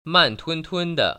[màntūntūn‧de]